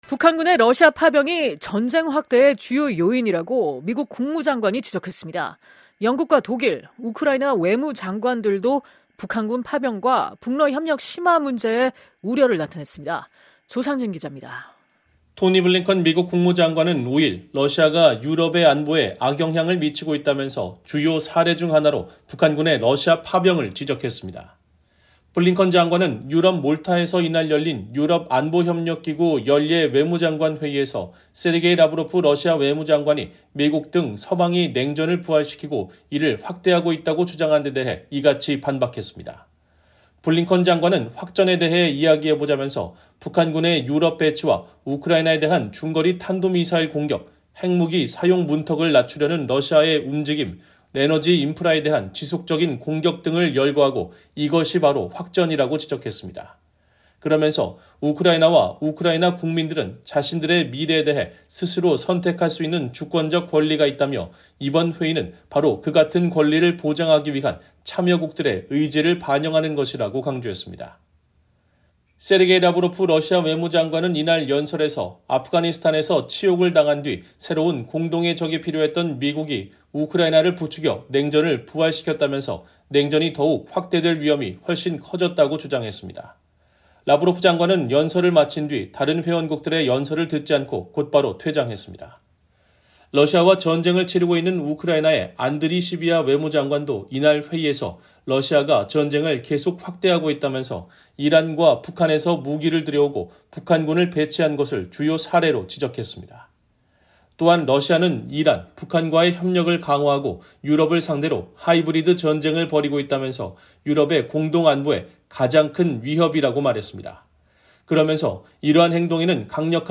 토니 블링컨 미국 국무장관이 2024년 12월 5일 몰타에서 열린 유럽안보협력기구(OSCE) 연례 외무장관 회의에서 발언하고 있다.